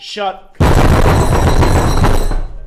loudnoise.mp3